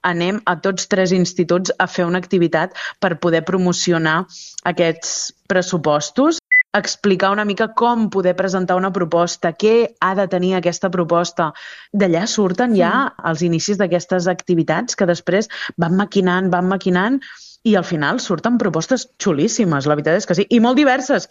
Ho ha explicat la regidora de Joventut, Mariceli Santarén, en una entrevista a l’Ona Maresme.